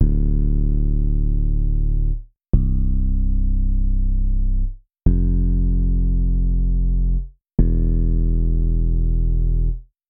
描述：低音炮